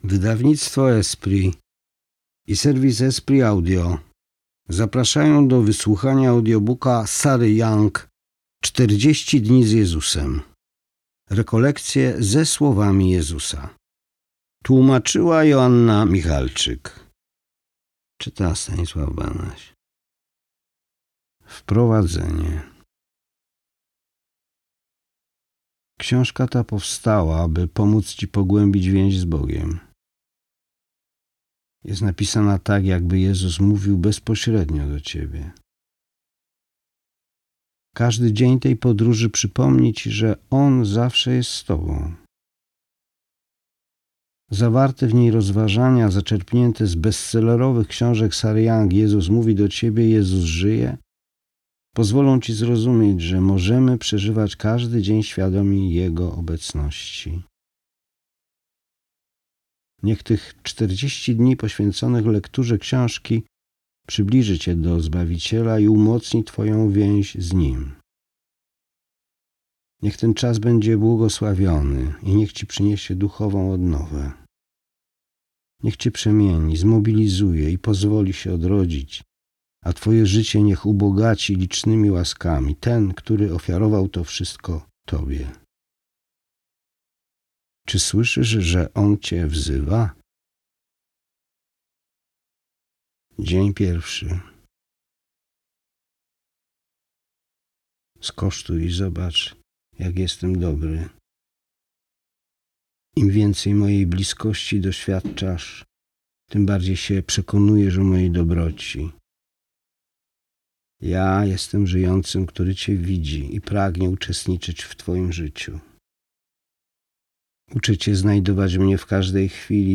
40 dni z Jezusem - Audiobook